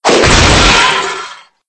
ENC_cogfall_apart_3.ogg